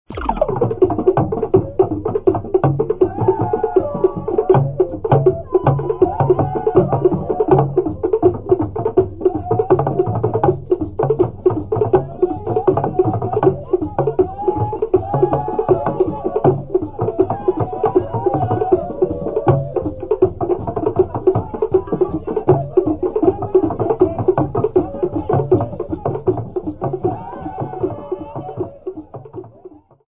Mayogo men and women
Folk music--Africa
Field recordings
sound recording-musical
Indigenous folk song for the 'Ebi dance', with singing accompanied by 2 conical, laced drums, 2 pod-shaped slitdrums, 1 large and 2 small cylindrical slitdrums, and 1 double metal bell.